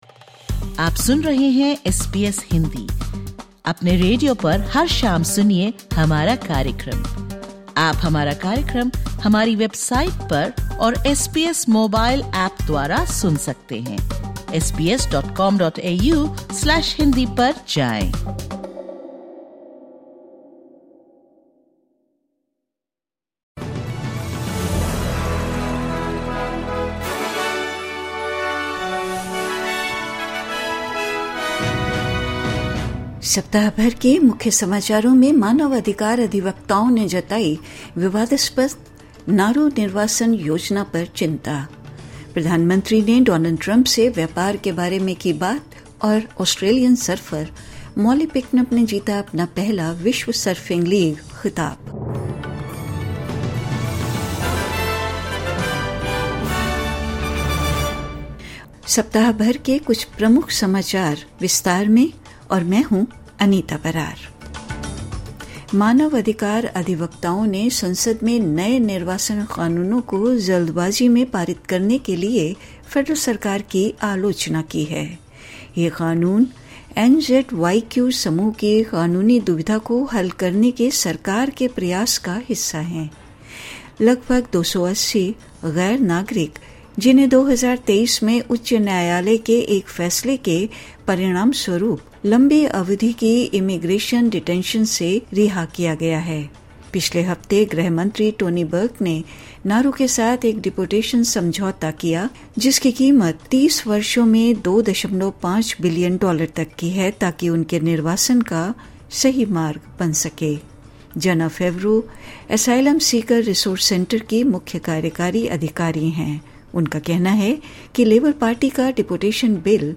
सप्ताह भर के समाचारों के इस बुलेटिन मे - मानवाधिकार अधिवक्ताओं ने जताई विवादास्पद नाउरू निर्वासन योजना पर चिंता प्रधानमंत्री ने डोनाल्ड ट्रंप से व्यापार के बारे में की बात